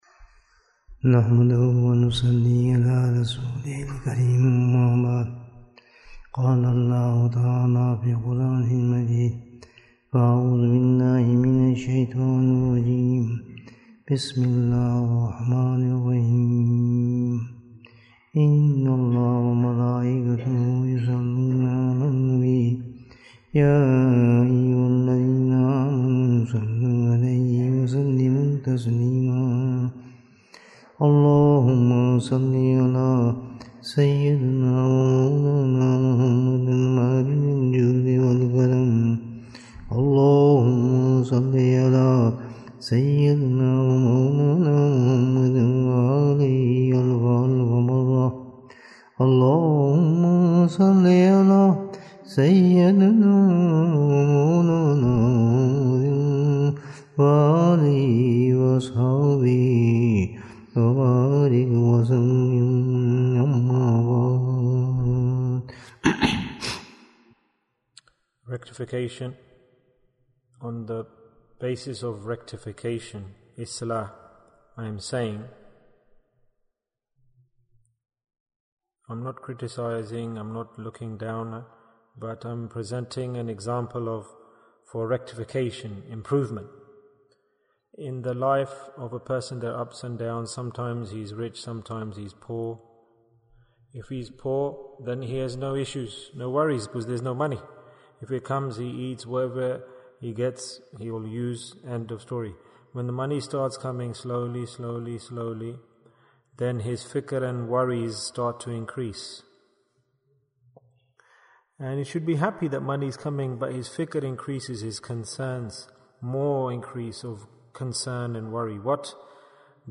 Bayans Clips Naat Sheets Store Live Which is the Lock for the Safety of Deeds? Bayan